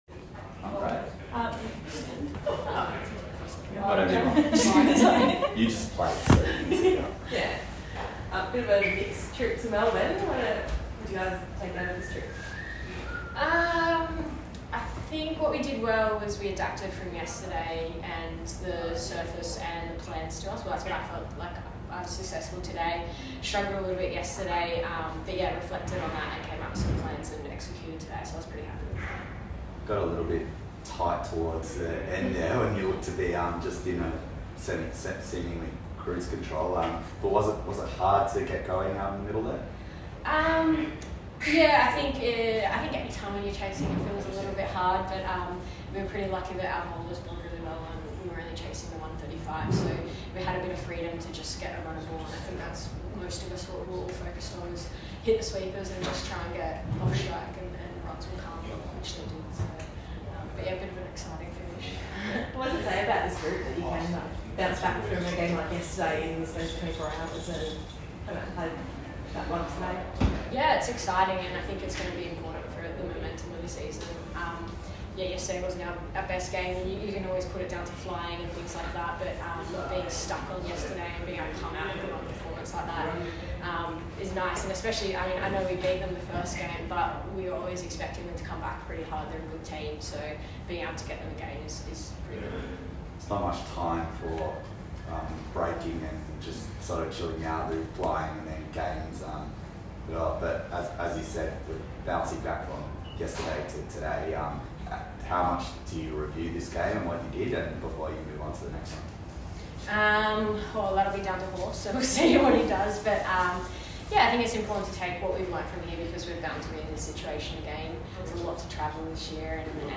Katie Mack after POTM performance in win v Stars post-match interview